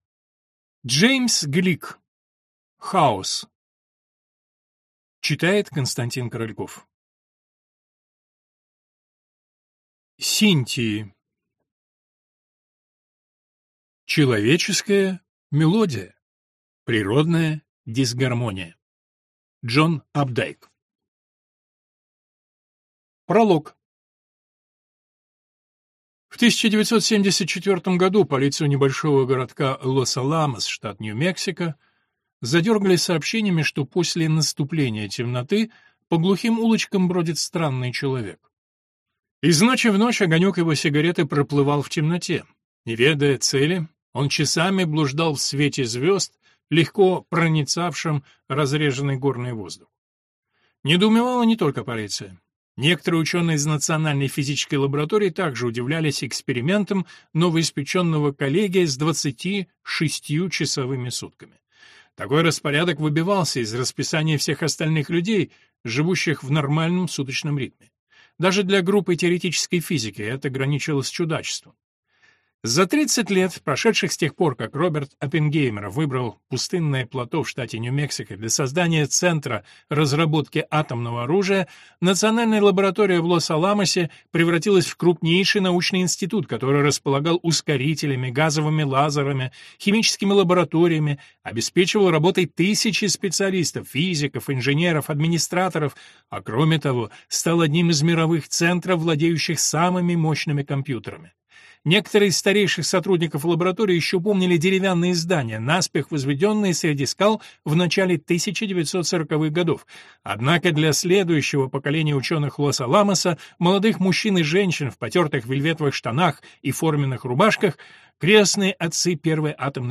Аудиокнига Хаос. Создание новой науки | Библиотека аудиокниг